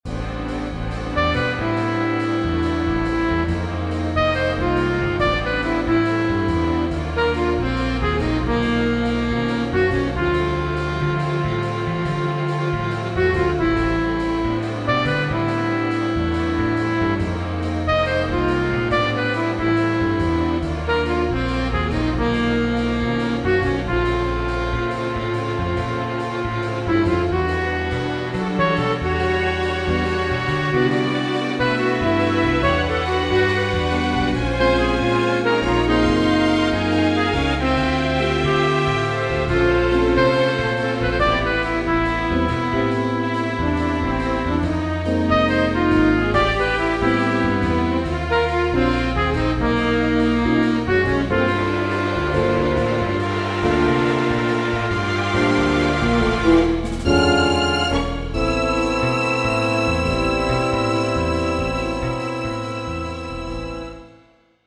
cellos, drumkit, bass guitar.